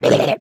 Minecraft Version Minecraft Version snapshot Latest Release | Latest Snapshot snapshot / assets / minecraft / sounds / entity / shulker / ambient5.ogg Compare With Compare With Latest Release | Latest Snapshot